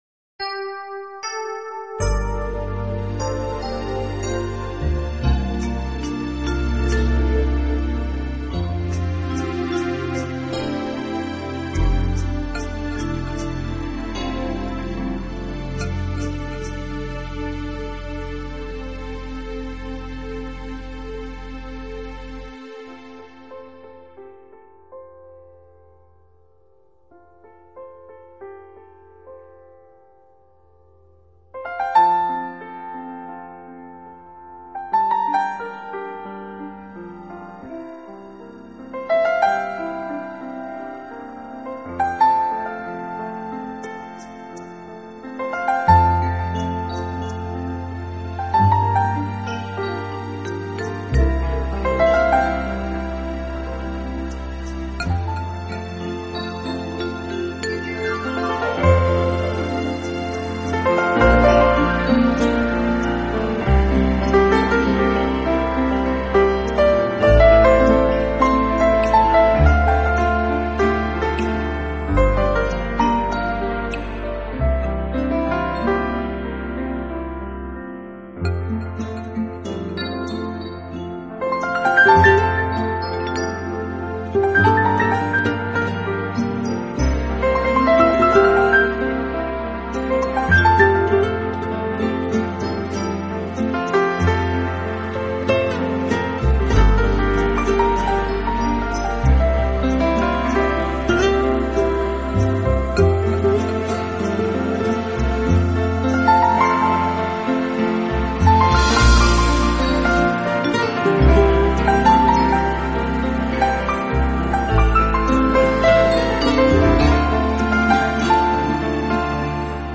【新世纪钢琴】
音乐类型: 钢琴